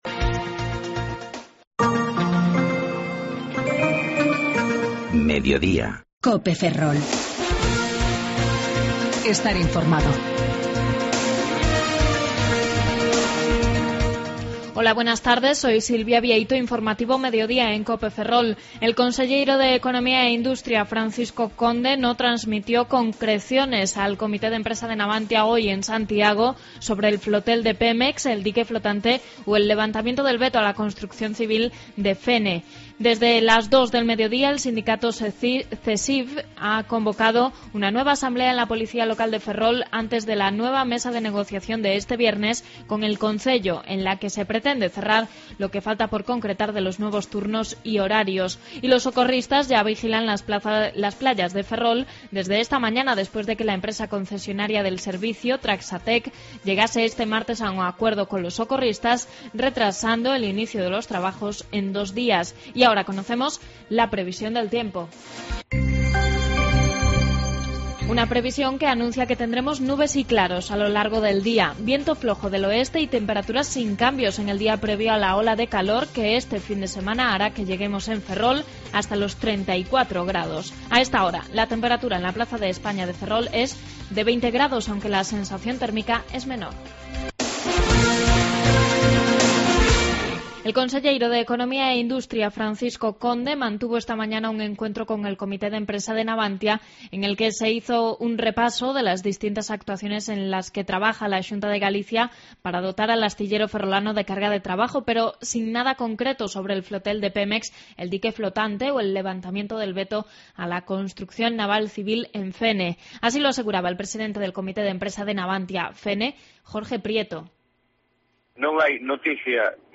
14.25 Informativo Mediodía Cope Ferrol